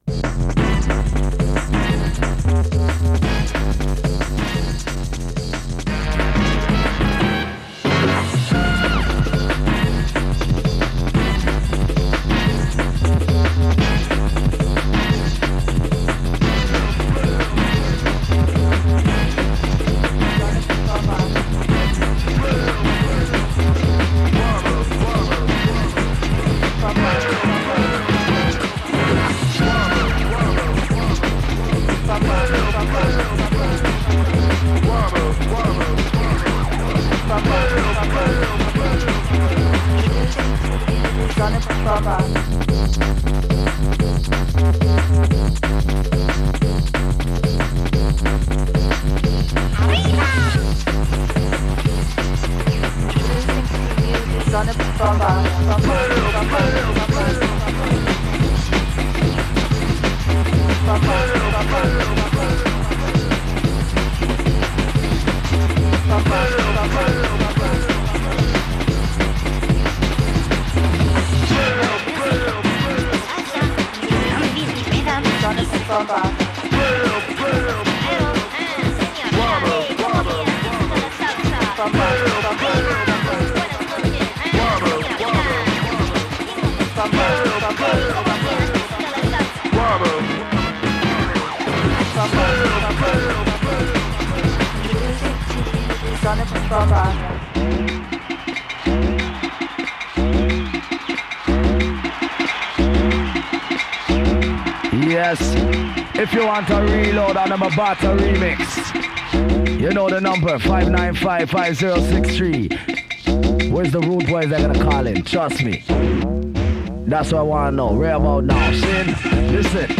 Stream the Jungle Tape Audio